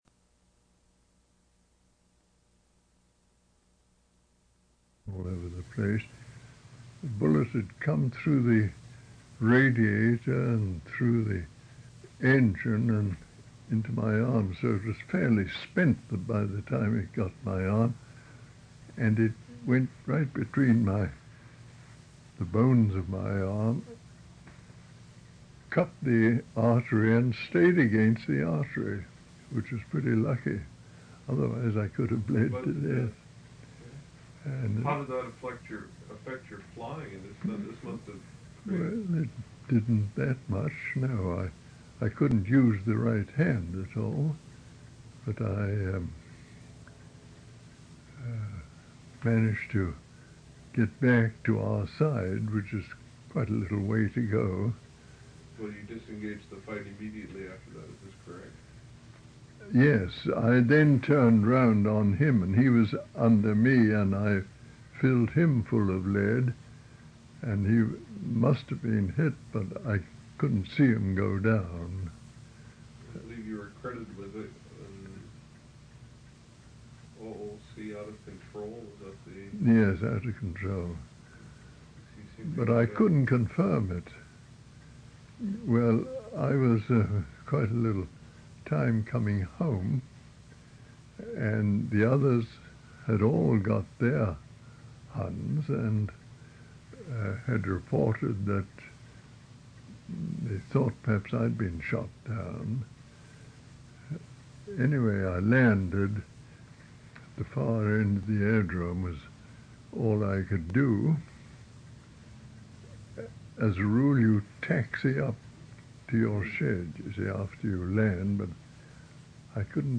Interview took place on August 24 and 28, 1978.